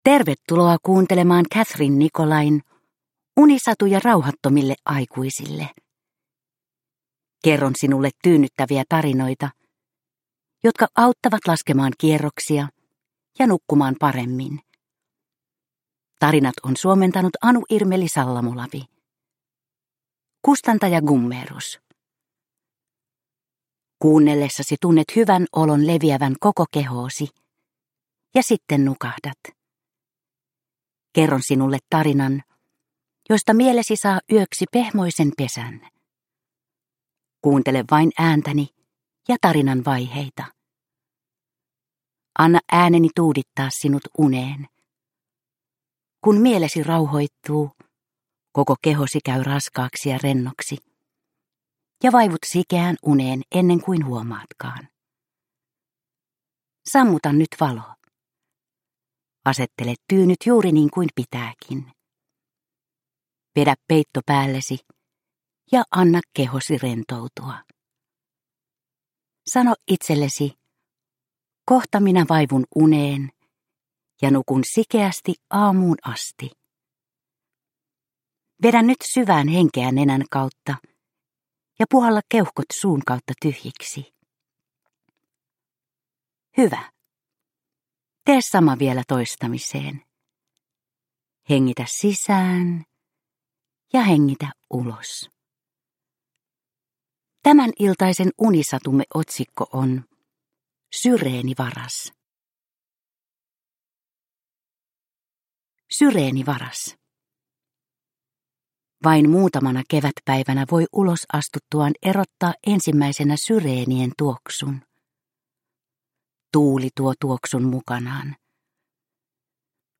Unisatuja rauhattomille aikuisille 33 - Syreenivaras – Ljudbok – Laddas ner